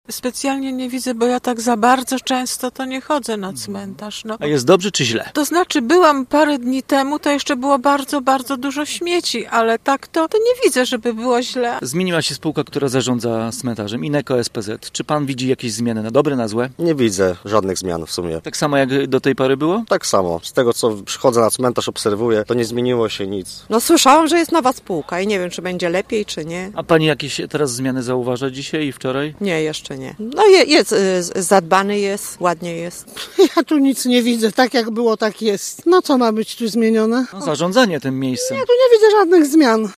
A to usłyszeliśmy dziś na ulicach Gorzowa: